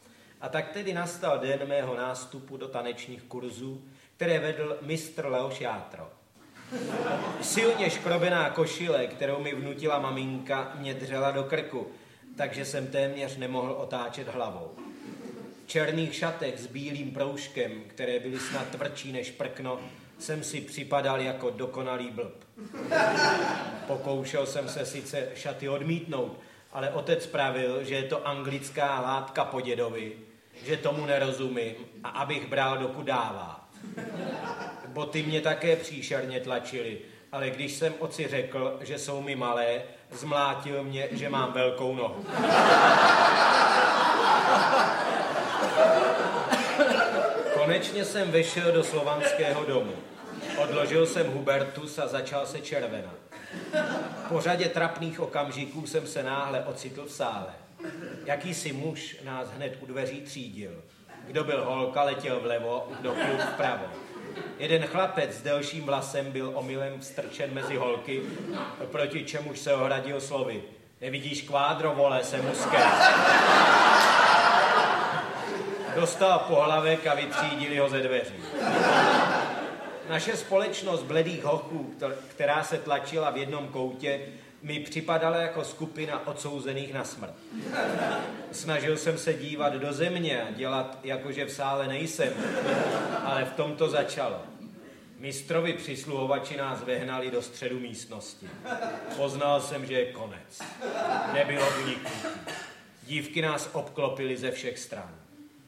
Povídky Šimka a Grossmanna 2 audiokniha
Audio kniha
Ukázka z knihy
Povídky psali oba autoři původně pro svá jevištní vystoupení. Proto také první dva tituly /Povídky Š+G 1 a 2/ vydal Supraphon v letech l994 a l995 jako výběr z archivních zvukových záznamů inscenací této dnes už slavné dvojice, včetně dochované nahrávky interpretace jedné povídky Jiřím Grossmannem.Kompilace obsahuje následující povídky:Můj první trampMé příhody z dětstvíKonec dluhuMoje první tanečníVýlet s LomcovákemJak mi bylo líto dětí z rozvrácených rodinJak jsme vdávali sestruZe zápisníku sportovceČistá láska vítězí
• InterpretMiloslav Šimek, Jiří Grossmann